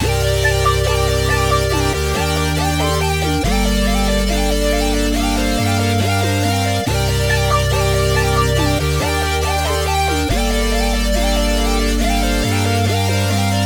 MELODY LOOPS
Honey (140 BPM – Gm)